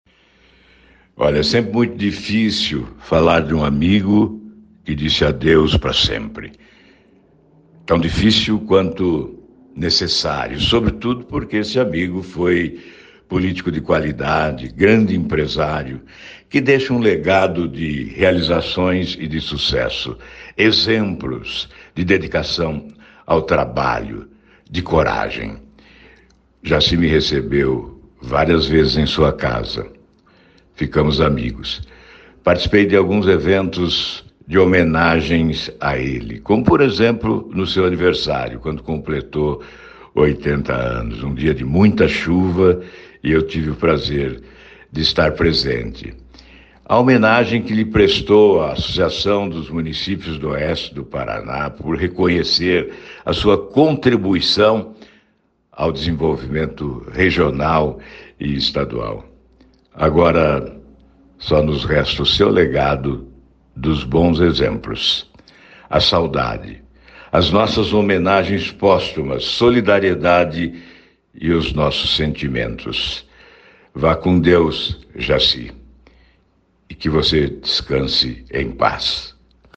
Player Ouça ALVARO DIAS, EX-SENADOR E EX-GOVERNADOR DO PARANÁ